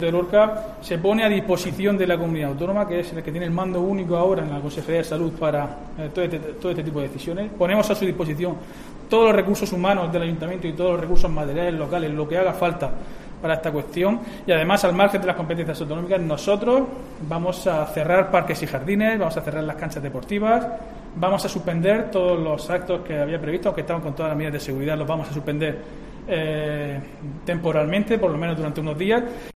Diego José Mateos, alcalde Lorca